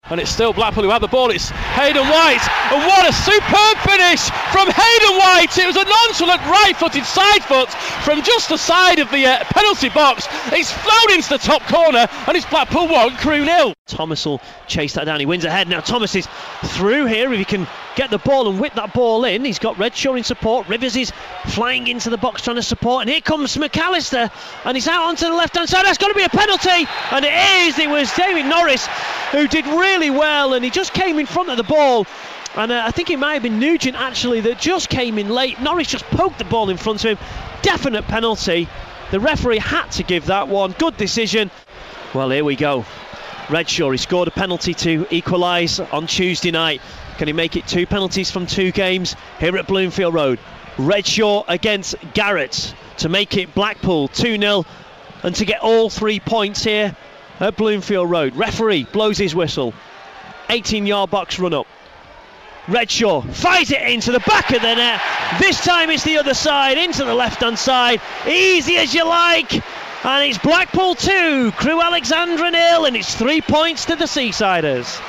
Highlights of the Blackpool v Crewe Alexandra game at Bloomfield Road, Blackpool stretched their unbeaten run to six games with a 2-0 victory